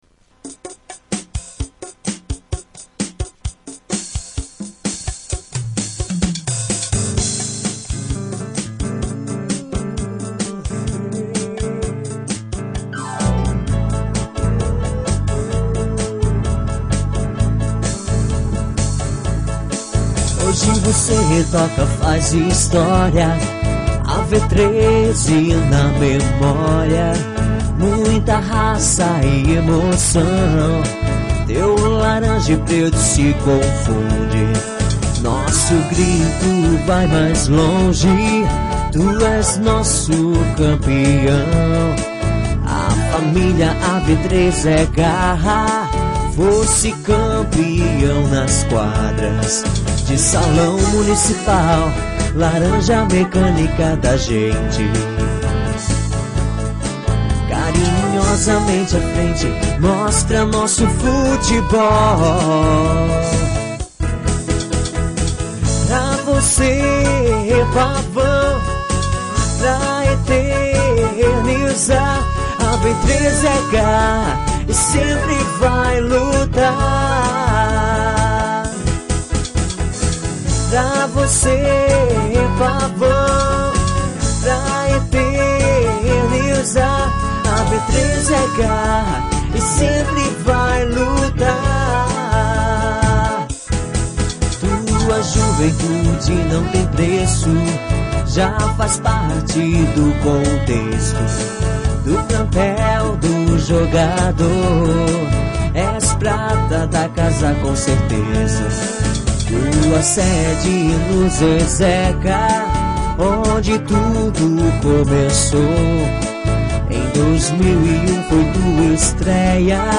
Composição: Laerte Moraes
Hino do AV 13-dance.mp3